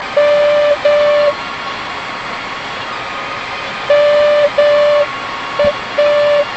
X20 Buzz tone